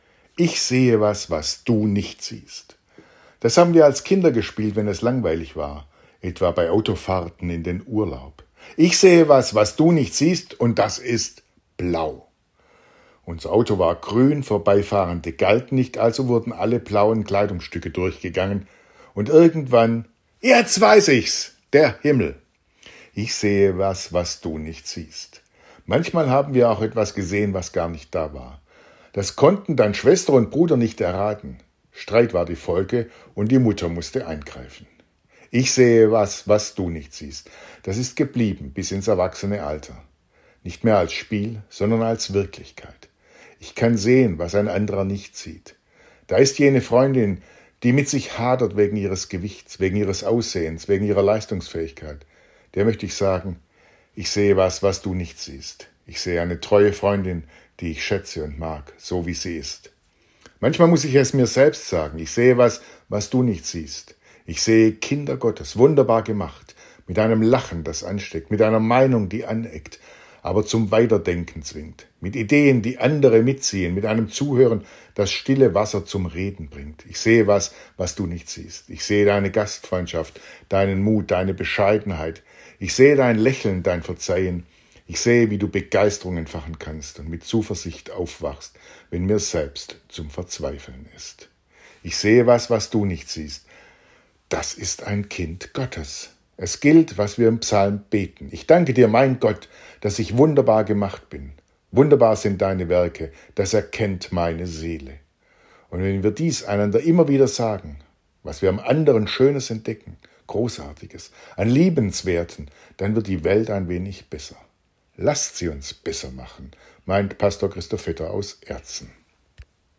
Radioandacht vom 27. Mai